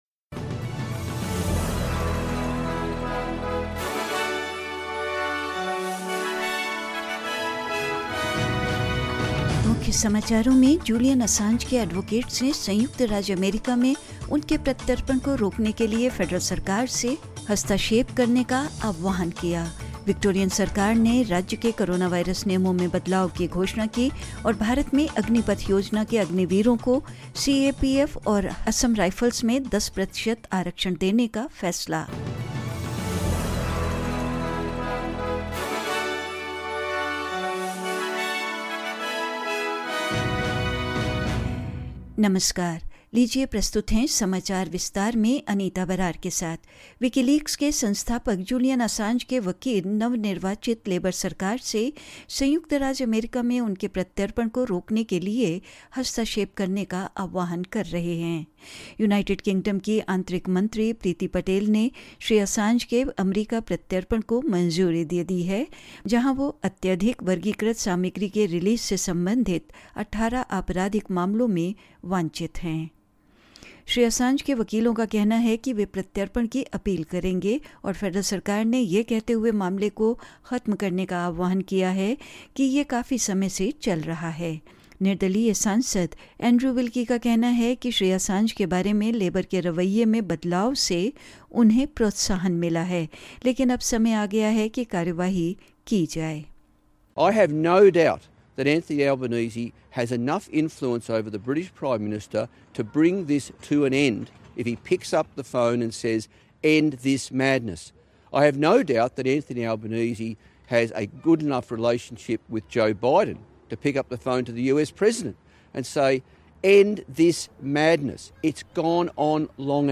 In this latest SBS Hindi bulletin: Advocates of Julian Assange call for the Federal Government to intervene to stop his extradition to the United States; The Victorian government announced changes to the state's coronavirus rules; In India, 10% quota for Agniveer in the Central Armed Paramilitary Forces (CAPFs) and Assam Rifles and more news.